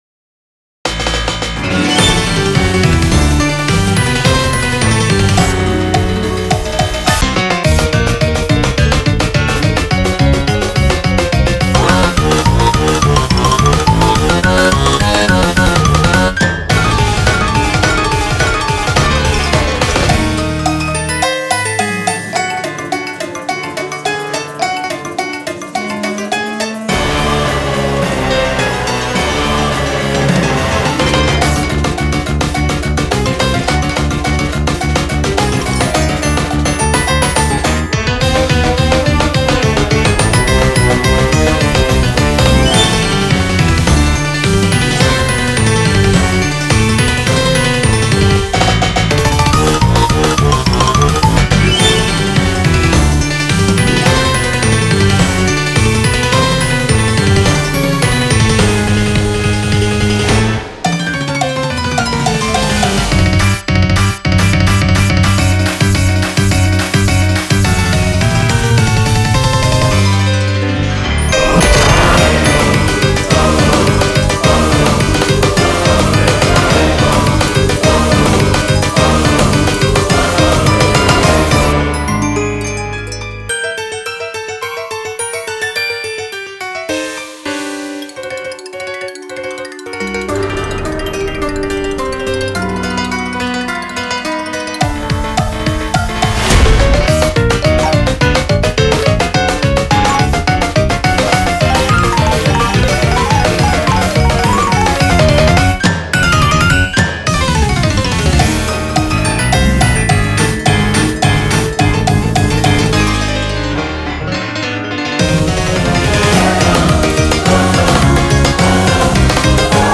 BPM212
Audio QualityMusic Cut